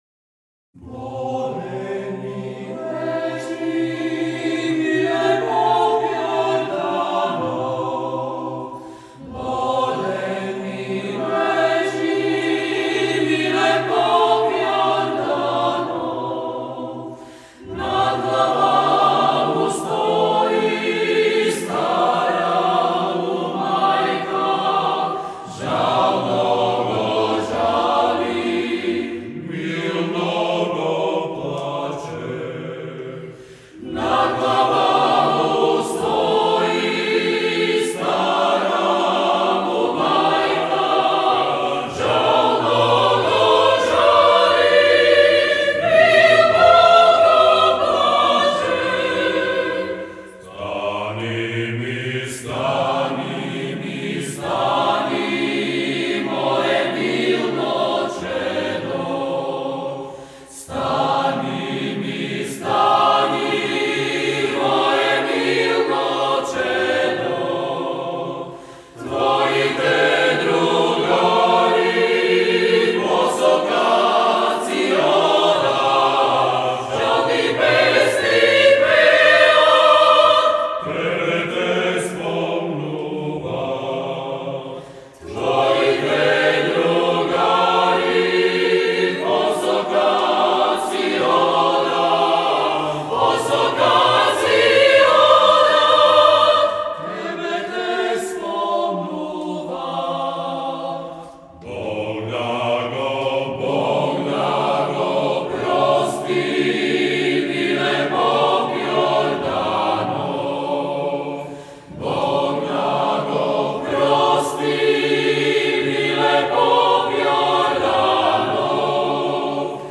акапела